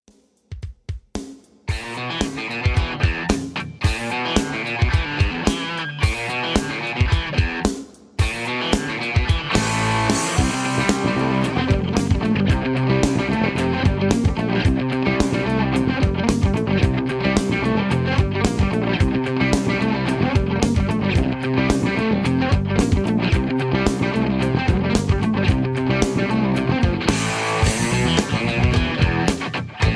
backing tracks, karaoke
rock